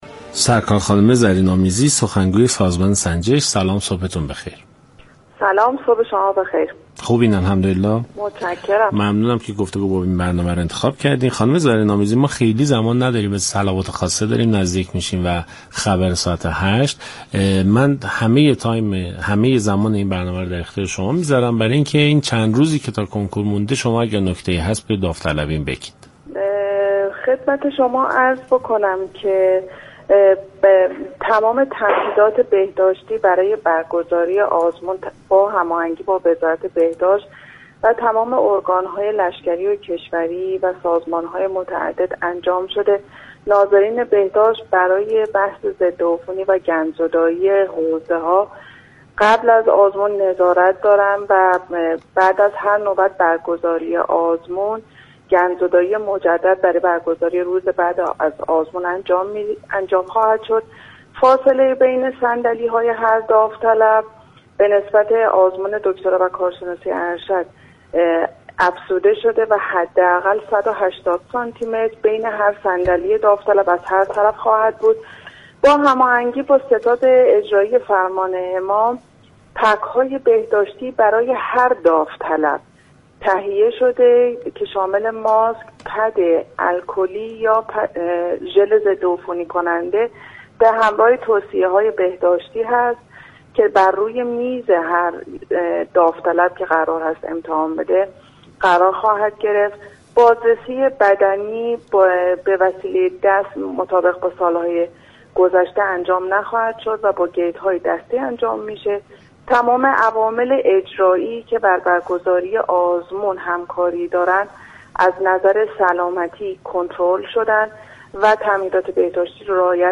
در ادامه این گفت و گو را می شنوید : دریافت فایل شخصیت مهم خبری